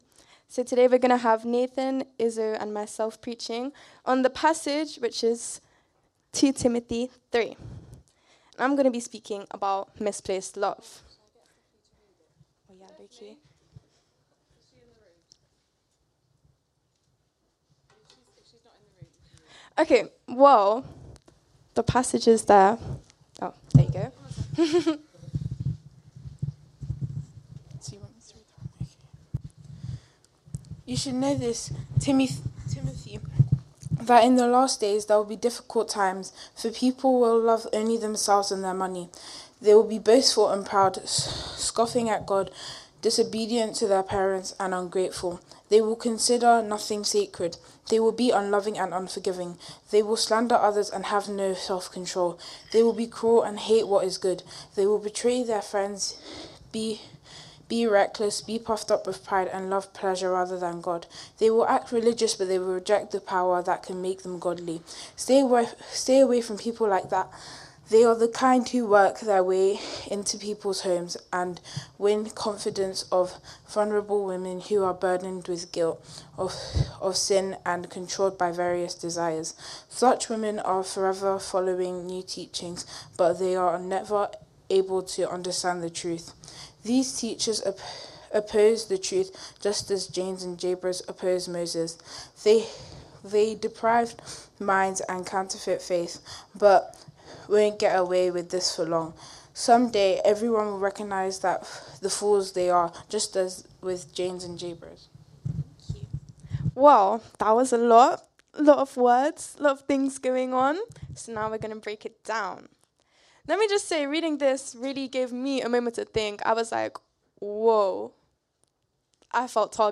Download Last Days | Sermons at Trinity Church